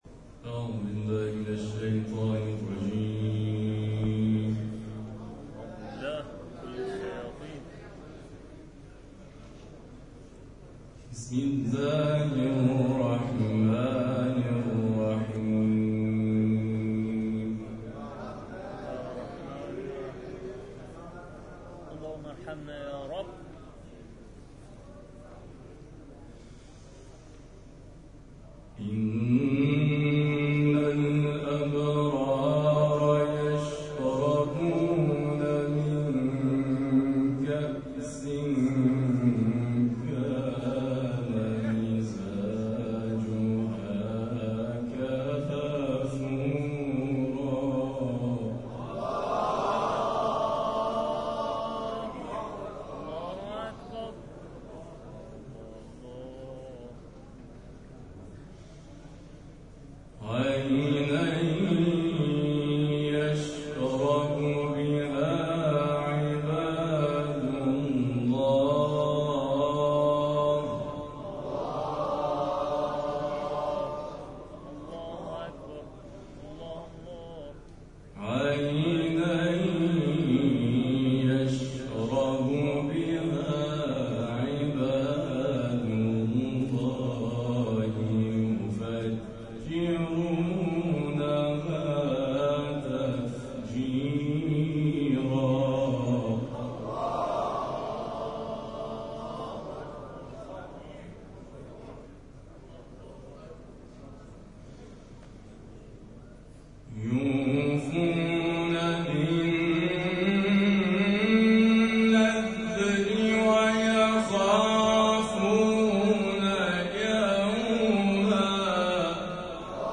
گروه جلسات و محافل: فینال فینالیست‌های دور اول جشنواره تلاوت مجلسی با عنوان «فجر تلاوت» با حضور اساتید و قاریان ممتاز و بین‌المللی در حسینیه صاحب الزمانی(عج) برگزار شد.